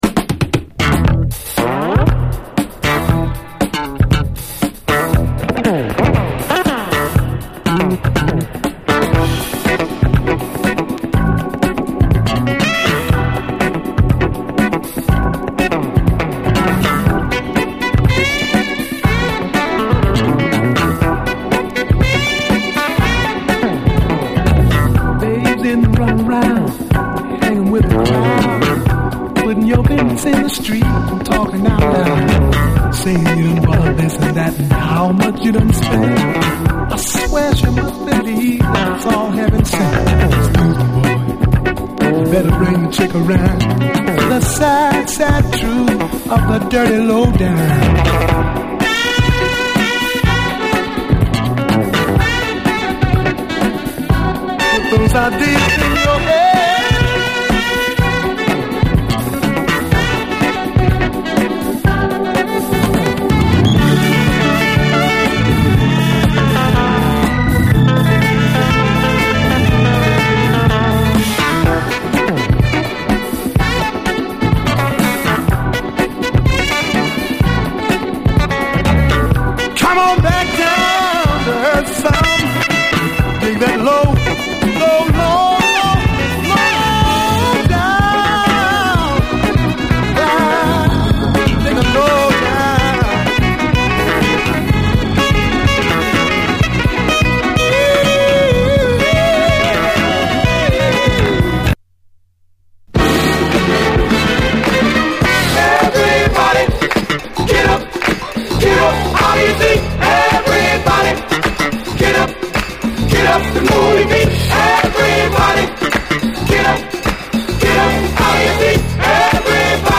SOUL, 70's～ SOUL, DISCO
強靭かつタイトすぎるファンクネスにブッ飛ばされる
イントロにブレイクも搭載！